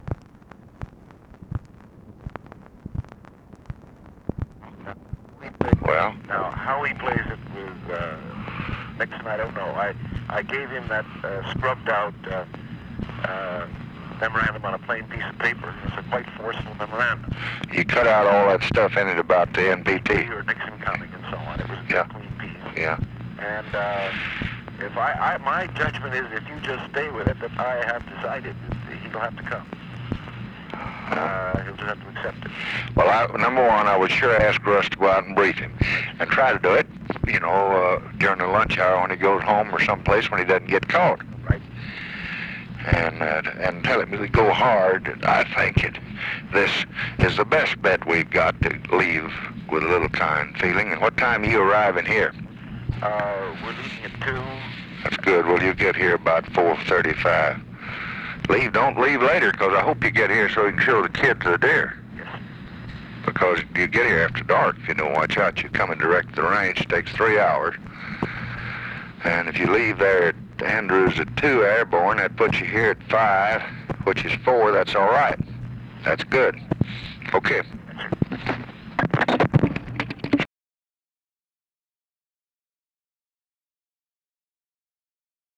Conversation with WALT ROSTOW, November 27, 1968
Secret White House Tapes